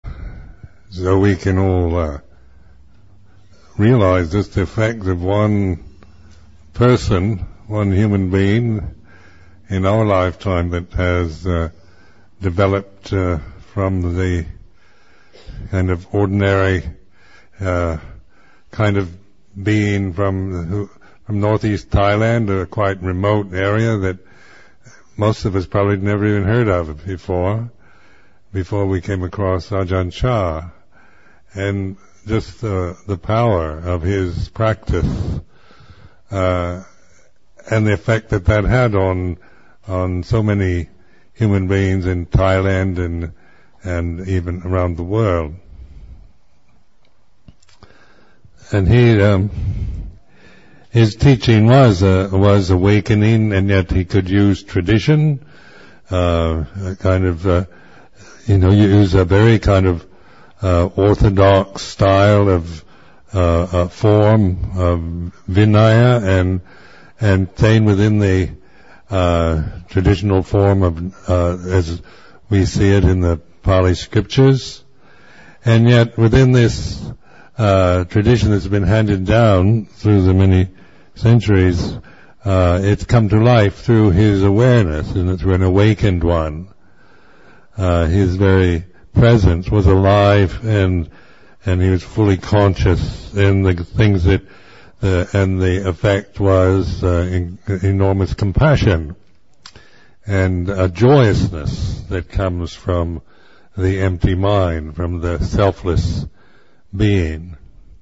1. Reflection by Ajahn Sumedho: The effect of one human being.